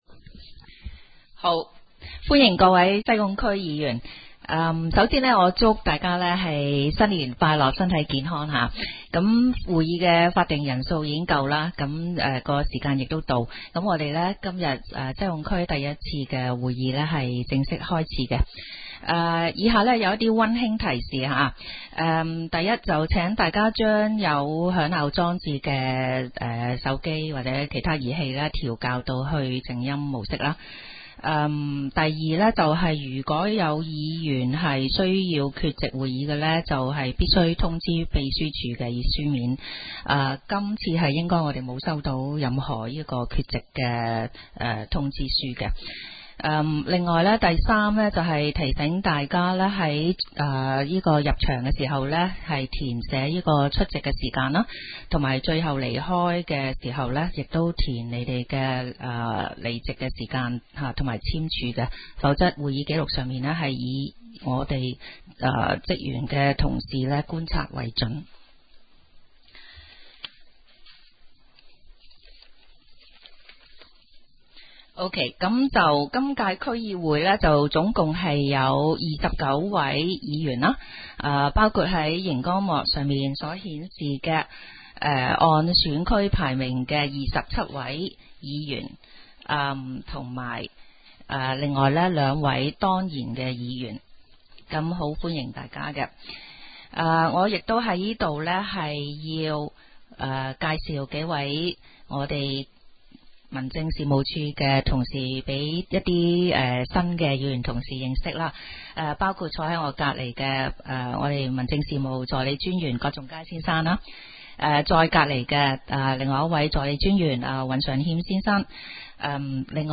西貢区议会第一次会议
三楼会议室